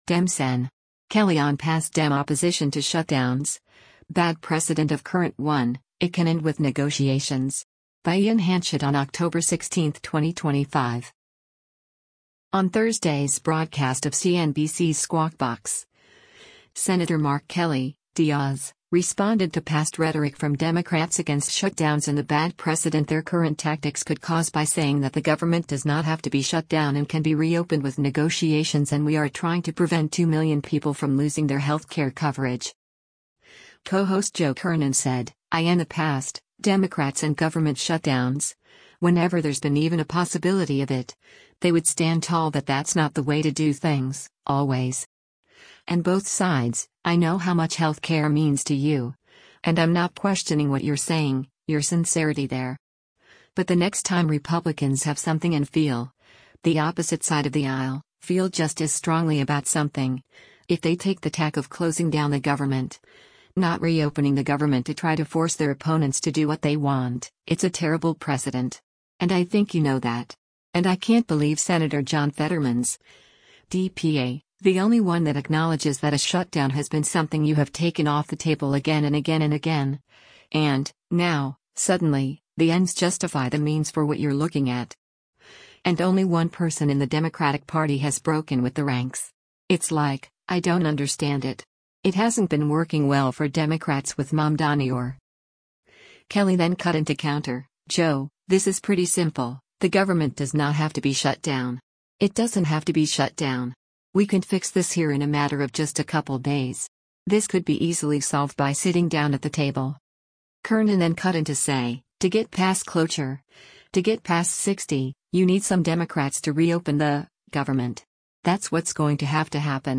On Thursday’s broadcast of CNBC’s “Squawk Box,” Sen. Mark Kelly (D-AZ) responded to past rhetoric from Democrats against shutdowns and the bad precedent their current tactics could cause by saying that “the government does not have to be shut down” and can be re-opened with negotiations and “we are trying to prevent two million people from losing their healthcare coverage.”